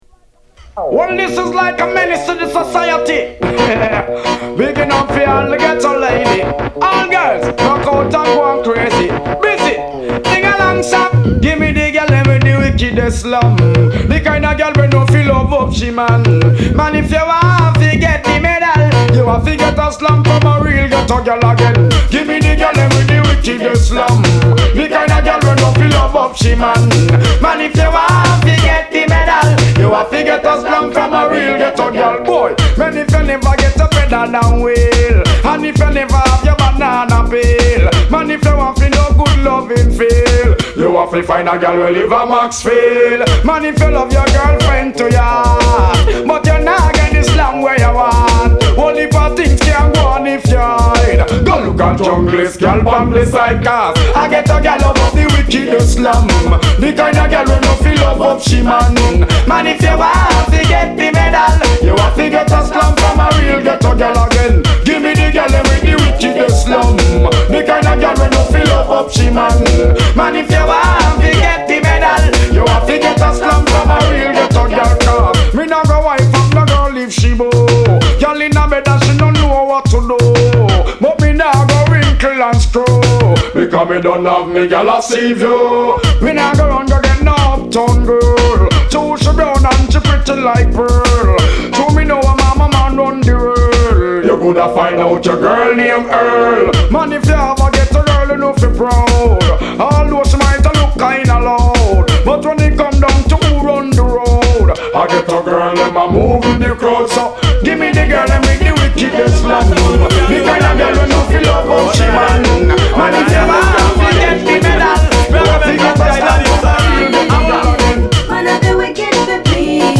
Strictly juggling of foundation hardcore riddims